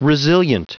Prononciation du mot resilient en anglais (fichier audio)
Prononciation du mot : resilient